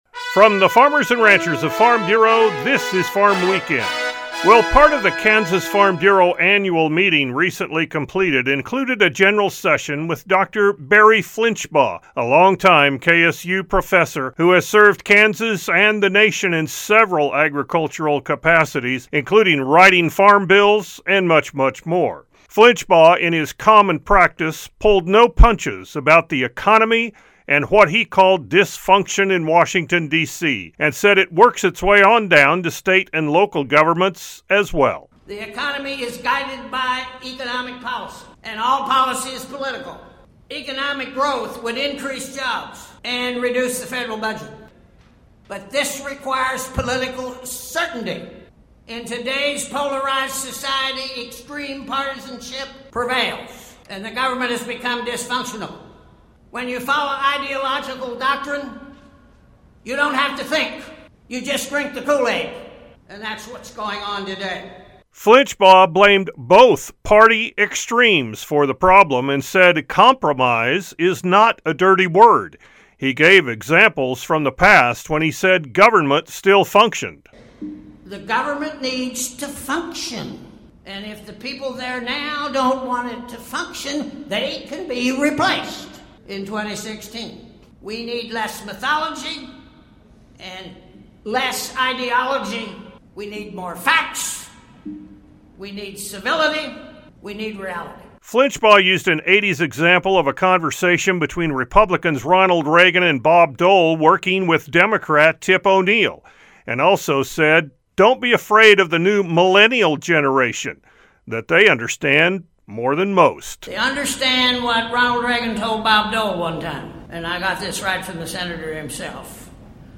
A 5-minute radio program featuring a re-cap of the weeks' agriculture-related news and commentary.